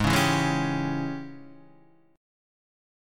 G# Major 7th Suspended 4th Sharp 5th